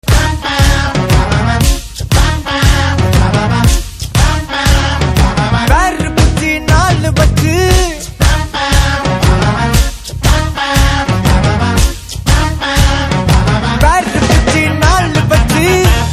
CategoryTamil Ringtones